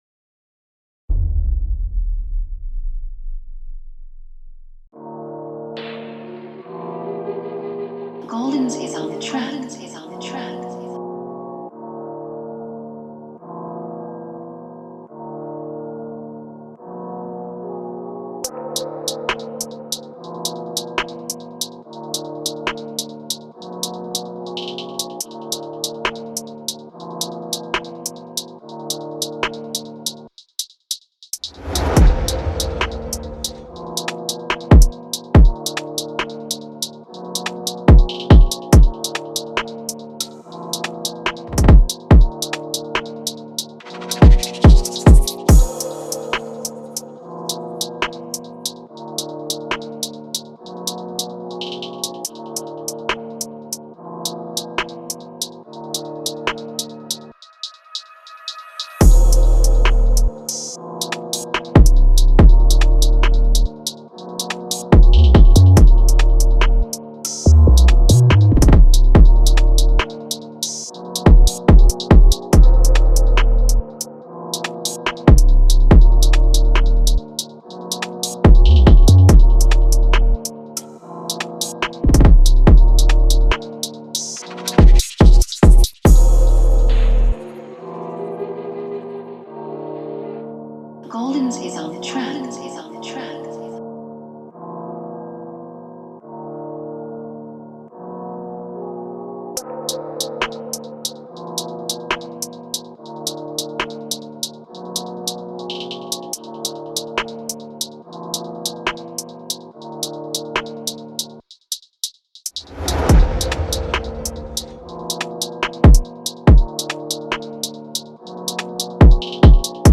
JFpuMOGJCcO_Instru-Rap-Drill-Freestyle-Lourd-2020.mp3